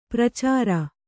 ♪ pracāra